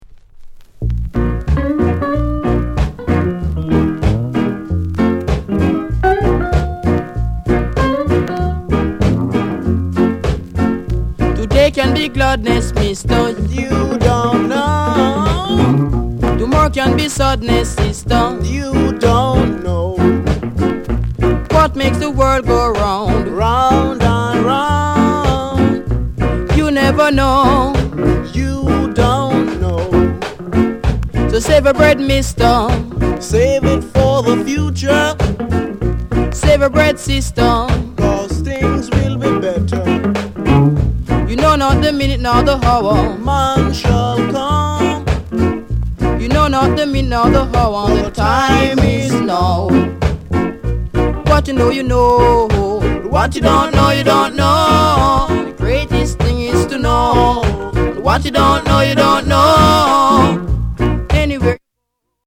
NICE ROCKSTEADY